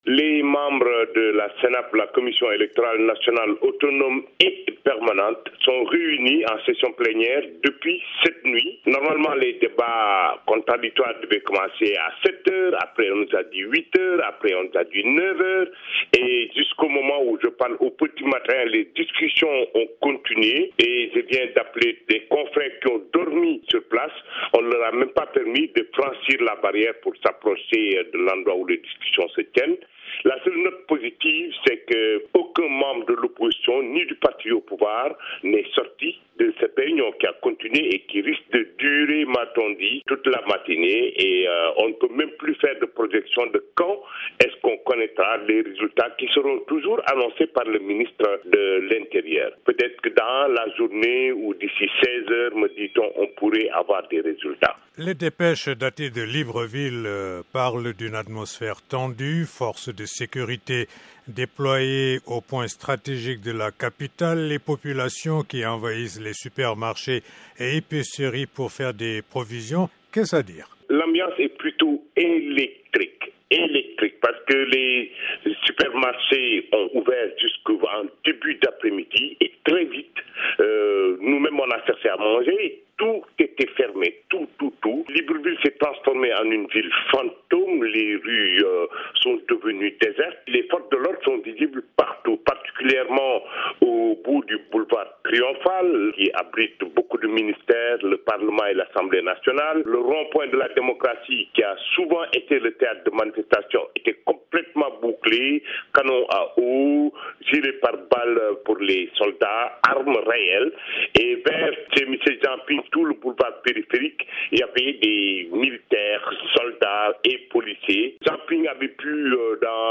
en direct dans notre édition de 5h30 T.U.